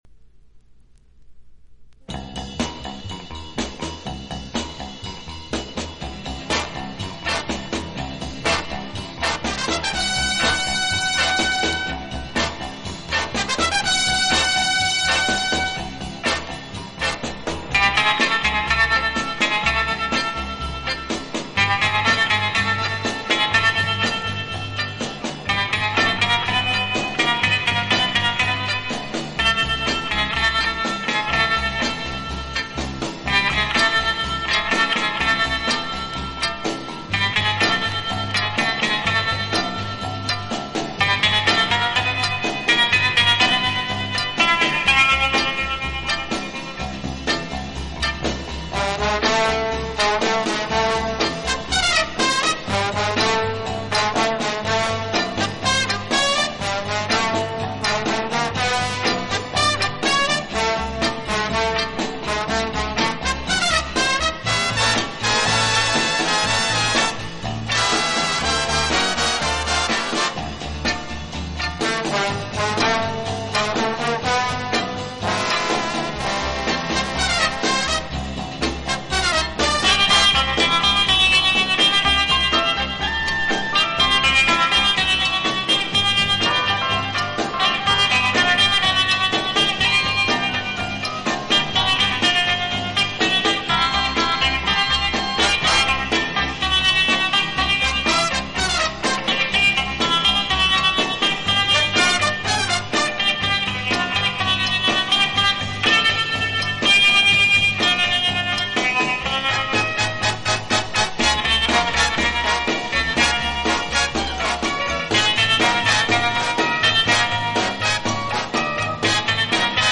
Genre: Instrumental, Easy Listening, Guitar